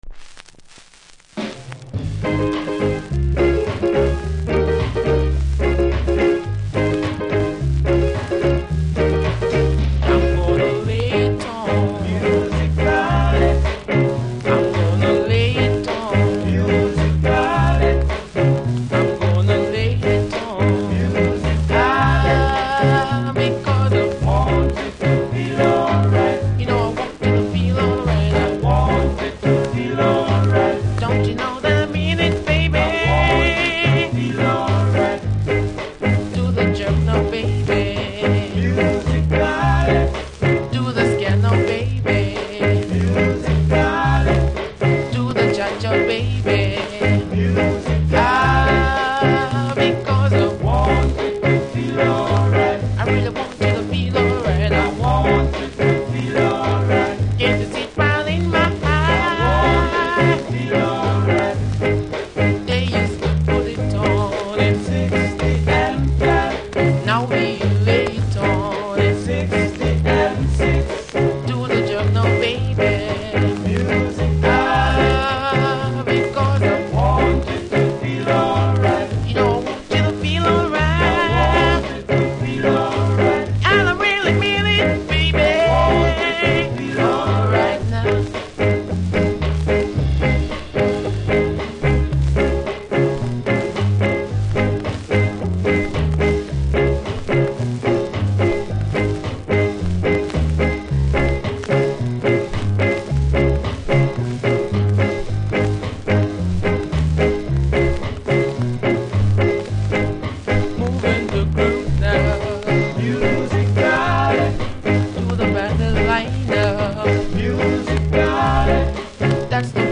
見た目キズは少ないですが所々ノイズ感じますので試聴で確認下さい。
ジャマイカ盤なので両面プレス起因のノイズあります。